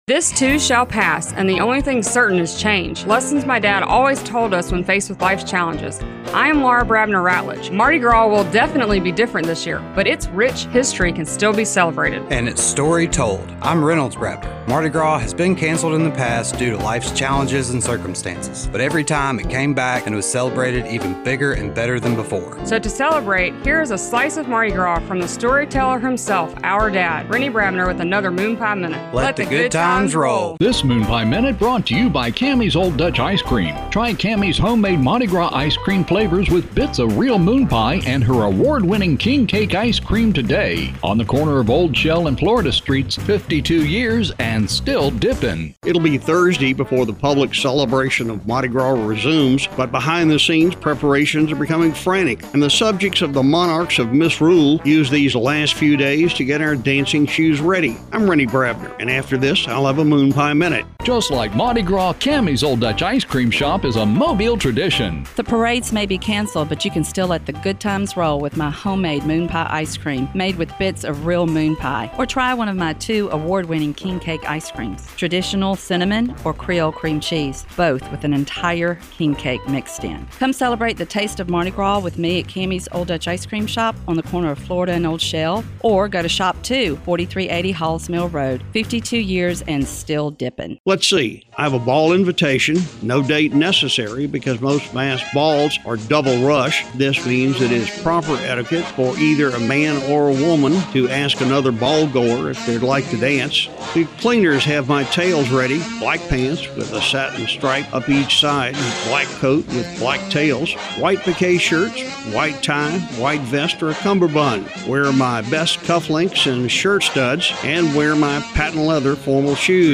enjoy this Moon Pie Minute from the story teller himself